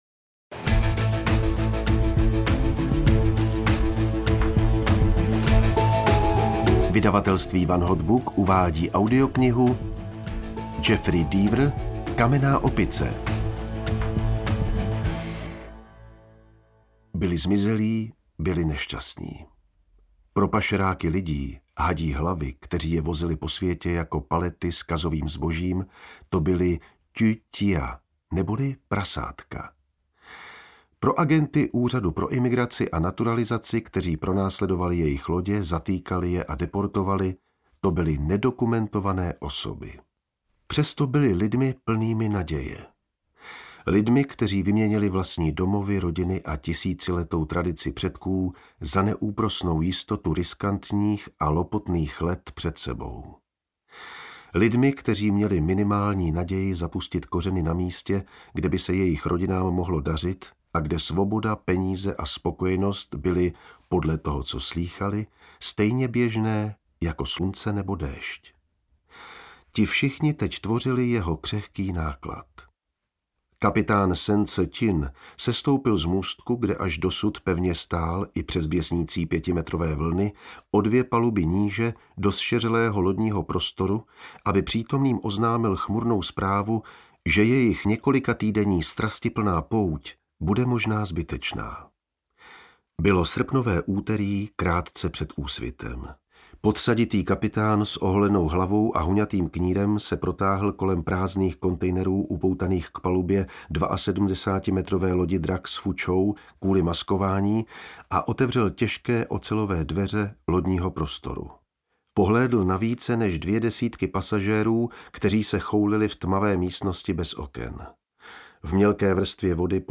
Kamenná opice audiokniha
Ukázka z knihy
Interpret pečlivě rozlišuje jednotlivé postavy, mění intonaci i tempo. V dramatických scénách čte rychle, vzrušeně, stejně dychtivě, jako by člověk hltal řádky textu v knize. V pochmurných pasážích naopak výrazně zpomalí, téměř strašidelně šeptá, až člověku běhá mráz po zádech.
• InterpretJan Vondráček